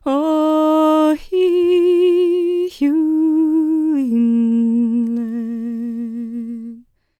L  MOURN A10.wav